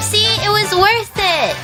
Worms speechbanks
Firstblood.wav